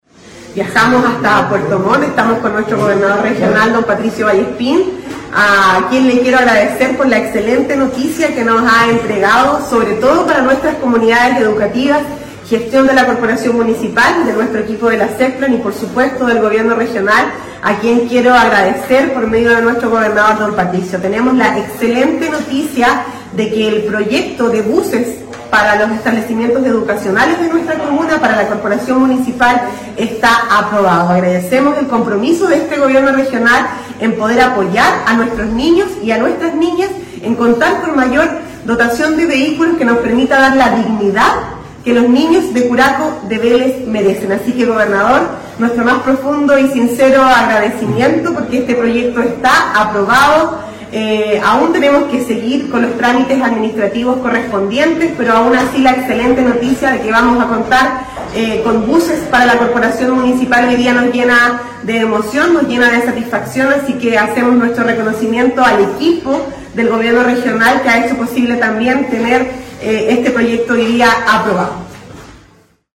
En este sentido, la máxima autoridad comunal enfatizó que es un nuevo paso que, una vez sea concretado, permitirá renovar los buses de la Corporación Municipal con el propósito de entregar mejores condiciones a los escolares tanto del sector urbano como rural:
ALCALDESA-BUSES-.mp3